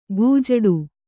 ♪ gojaḍu